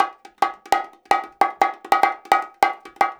150BONGO 2.wav